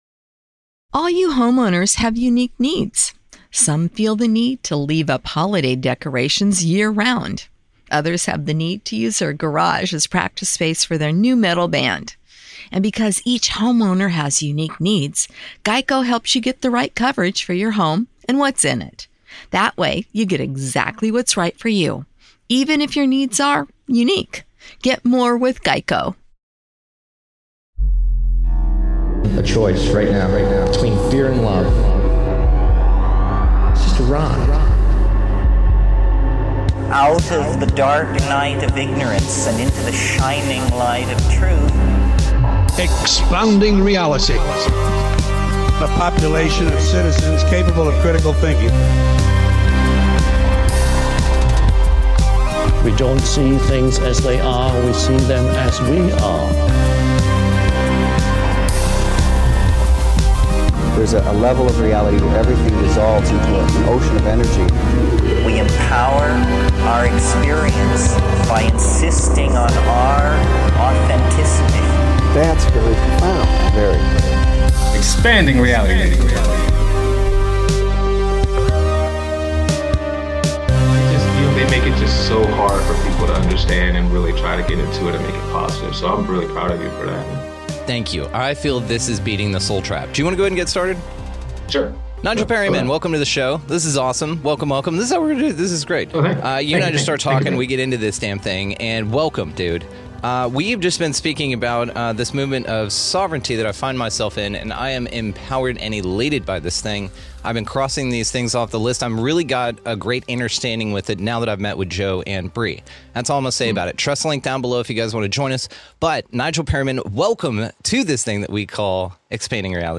We cover quite a bit of ground with mental metaphysics, celestial and extraterrestrial communication, the origins of imagination, angels ringing your ears and ultra-sonic sound transdimensional communication. Just an incredibly awesome conversation with one of my favorite souls ever.